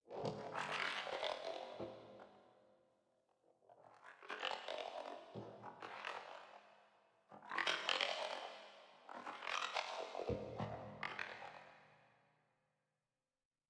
描述：听一些液体机器人说话。
标签： 复古 类似物 科幻 语音 聊天 机器人 振荡器 电路
声道立体声